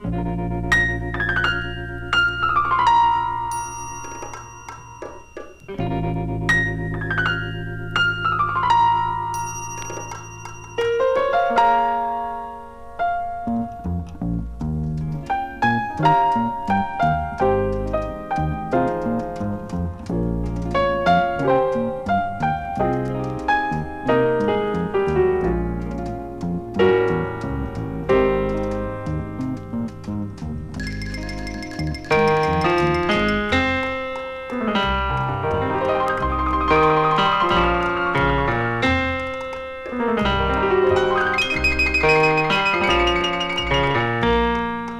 ステレオ録音技術の躍進もあり、スピーカーの片方ずつにピアノを配置。
味わいと深みある音が素晴らしい作品です。